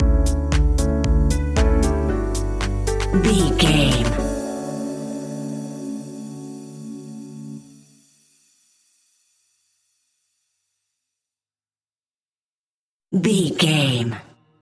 Ionian/Major
D
uplifting
energetic
bouncy
electric piano
drum machine
synthesiser
progressive house
synth leads
synth bass